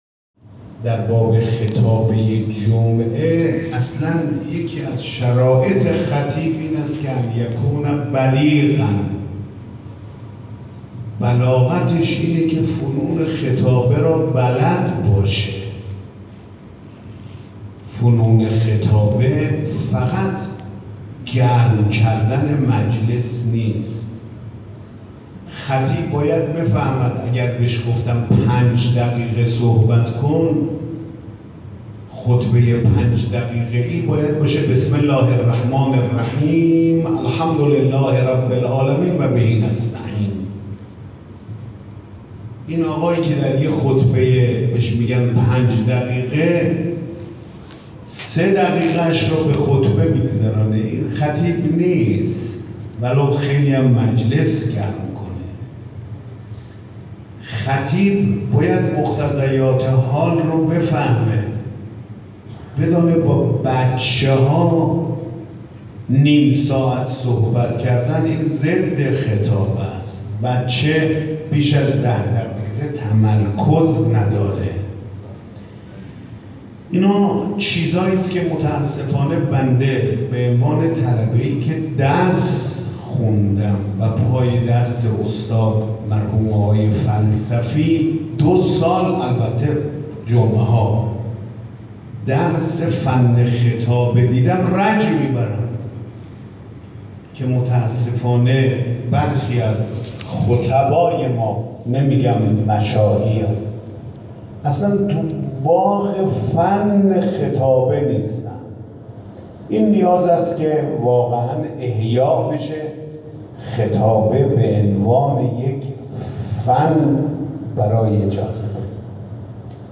به گزارش خبرنگار خبرگزاری رسا در کاشان، آیت الله سید احمد خاتمی ظهر امروز در همایش بزرگ حوزویان و مبلغان و ائمه جماعات شهرستان‌های کاشان و آران و بیدگل که در حسینیه شهدای پاسدار ناحیه مقاومت بسیج سپاه کاشان برگزار شد، گفت: خطبا از ظرفیت و فرصت طلایی ماه محرم بیشترین بهره برداری را برای تبلیغ موضوعات مهم داشته باشند.